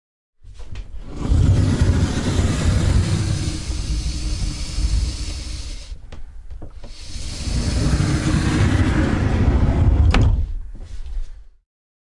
衣柜推拉门 " 衣柜推拉门开合慢速金属 006
对于这个录音我使用我的Sennheiser MKH416进入声音设备mp1前置放大器并录制到Tascam DR680。
Tag: 滑动 打开 关闭 衣柜 金属 家用电器 弗利